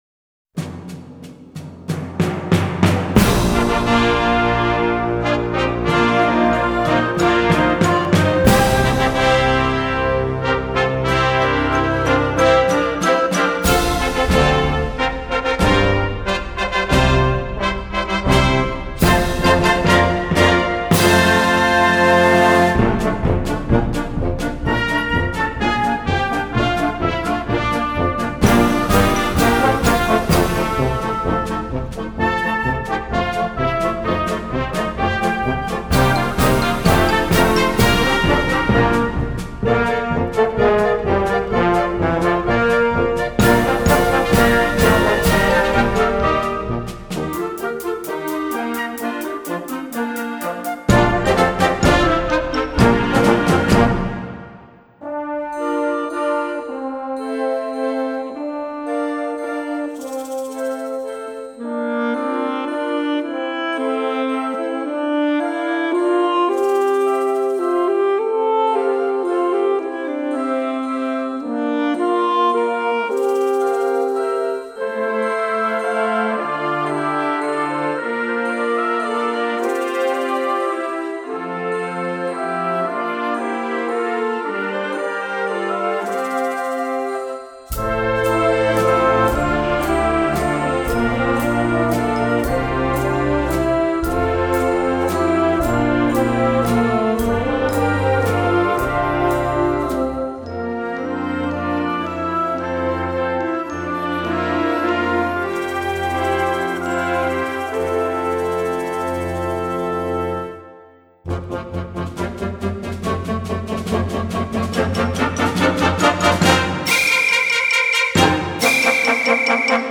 Gattung: Konzertstück für Jugendblasorchester
Besetzung: Blasorchester